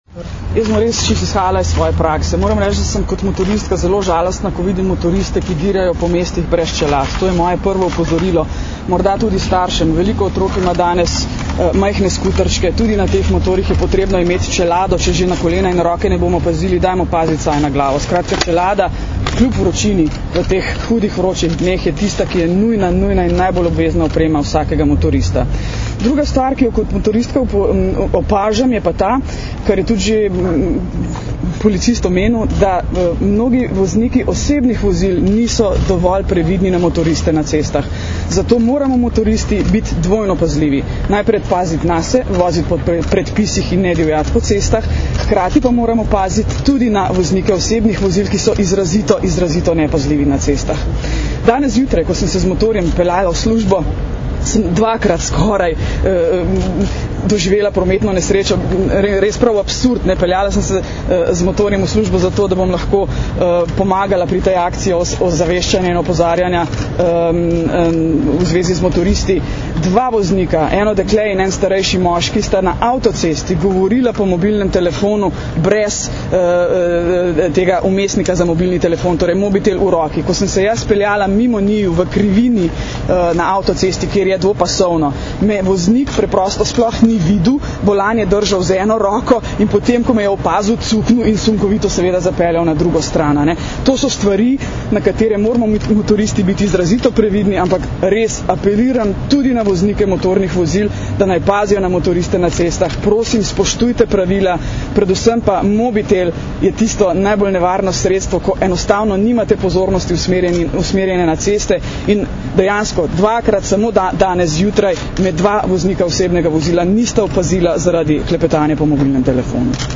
Zvočni posnetek izjave Nataše Pirc Musar (mp3)